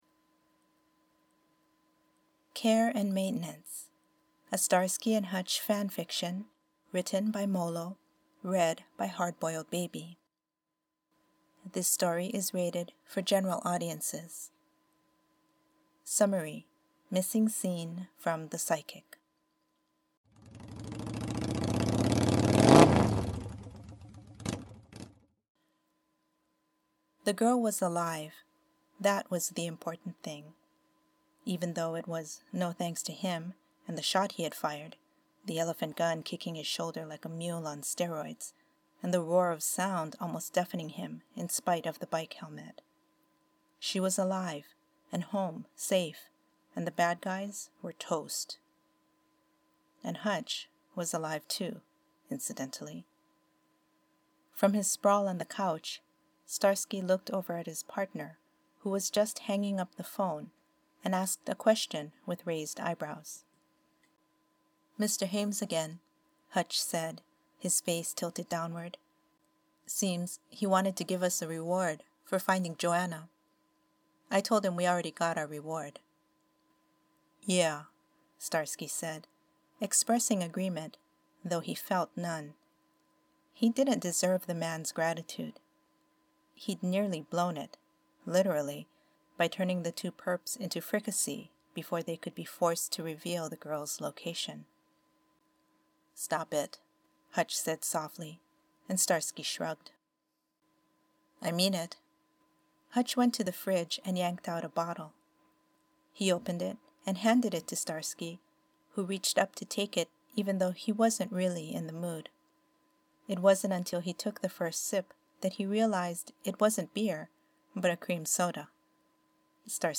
I love the way Starsky's voice breaks on "Hu-utch." Plus the motorcycle sounds.